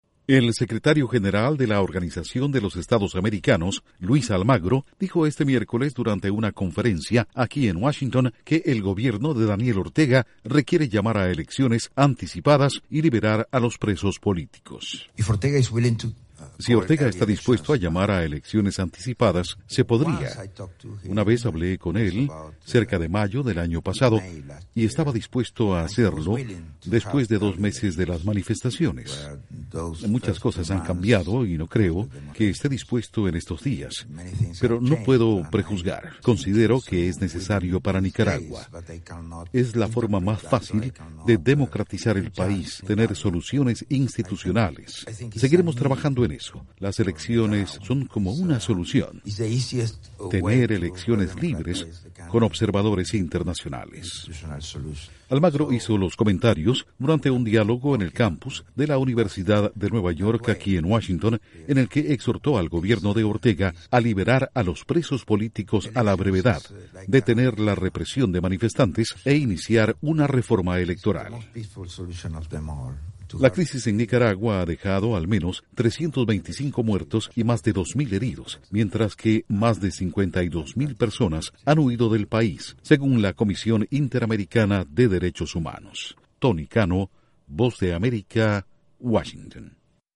Duración: 1:30 Con declaraciones de Luis Almagro/Secretario general de la OEA